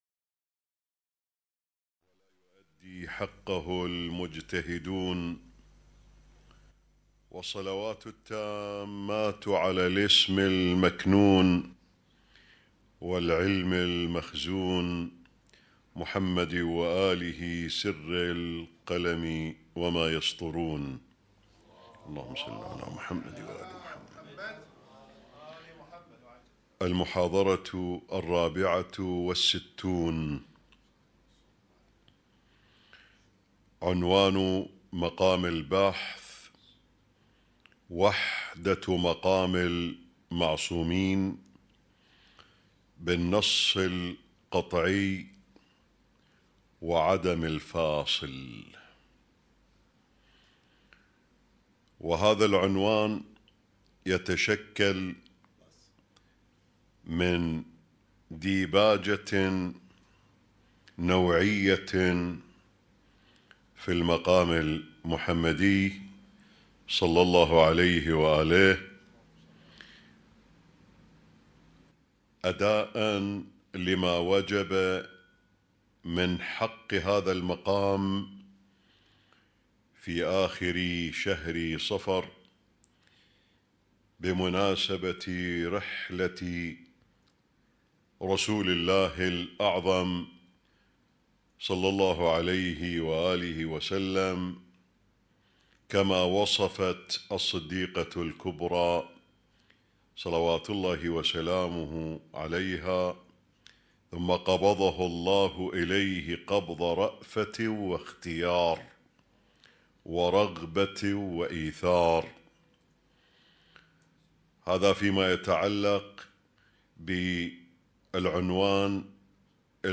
اسم التصنيف: المـكتبة الصــوتيه >> الدروس الصوتية >> الرؤية المعرفية الهادفة